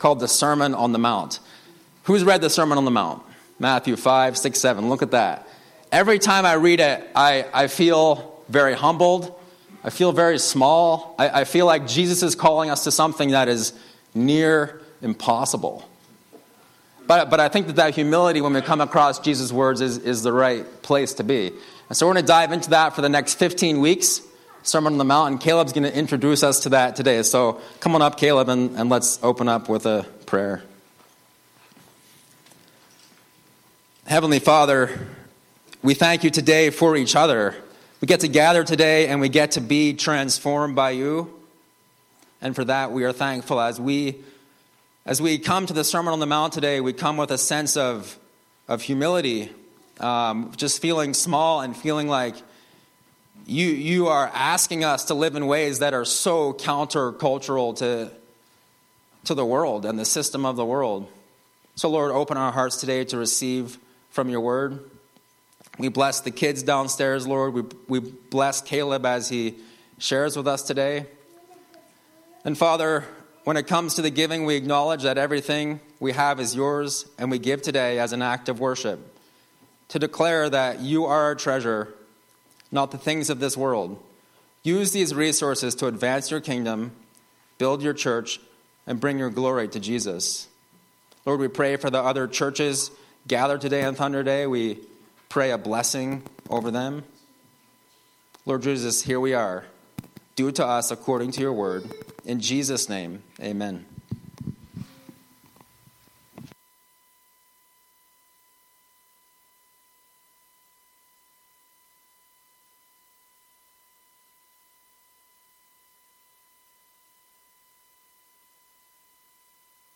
Intro Sermon, Sermon on the Mount